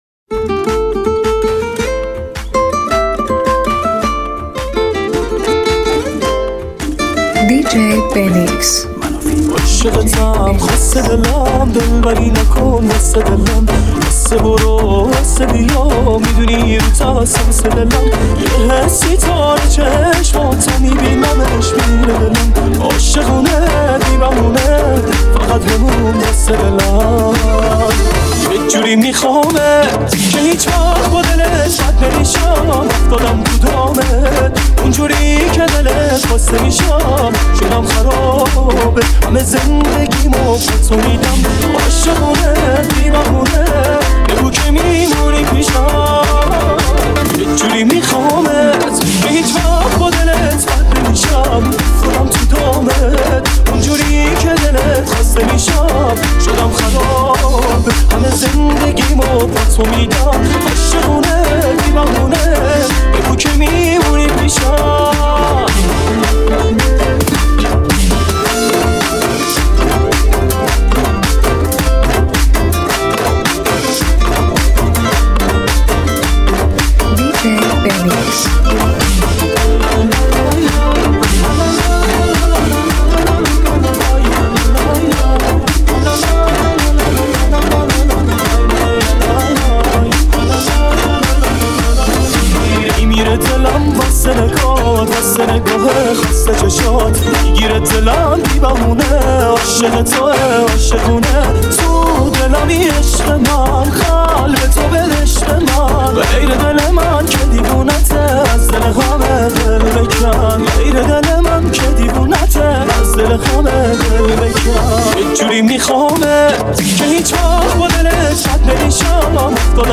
ریمیکس شاد و پر انرژی